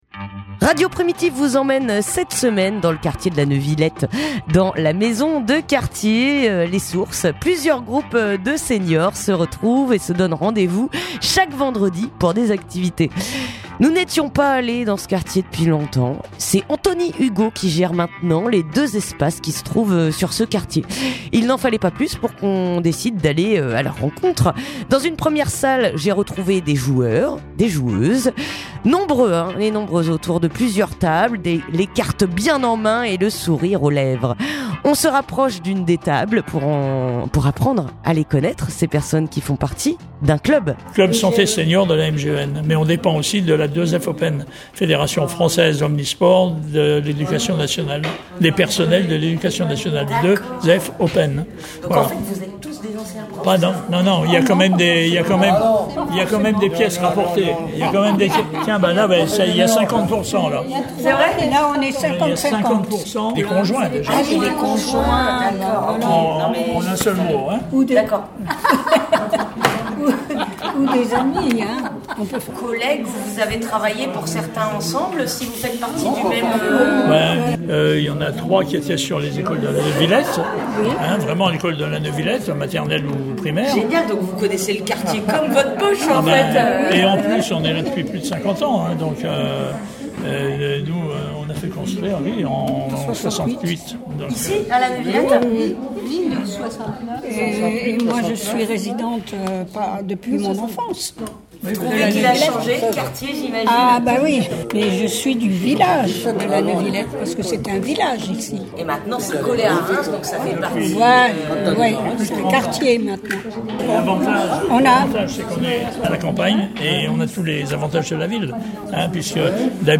Reportage à la Neuvillette (14:28)